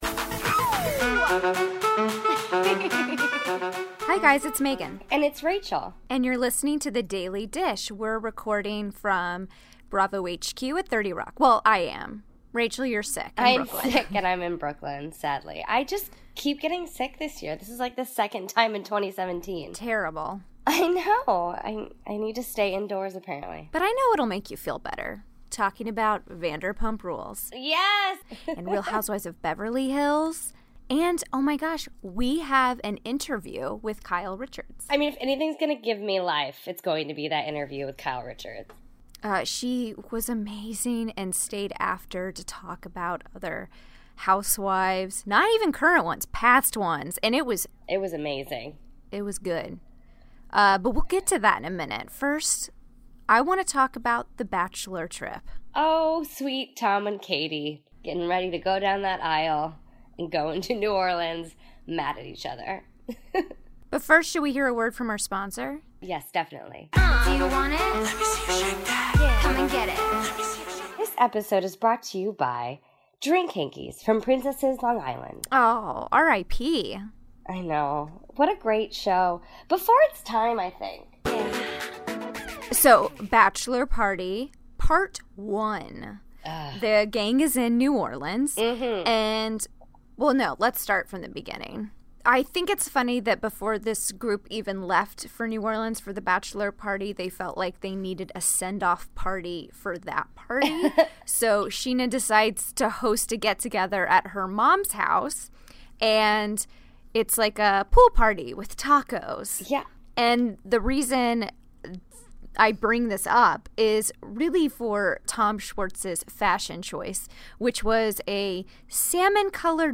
From Bravo HQ in New York City, we’re talking about this week’s Vanderpump Rules: Scheana takes boudoir photos, Katie drops a Bachelor reference, Jax apologizes to Stassi (and all women), and Sandoval ruins everything.
Plus - we sat down with none other than Kyle Richards! She showed off her excellent LVP impression and talks about her new show, her family, and what’s to come on RHOBH.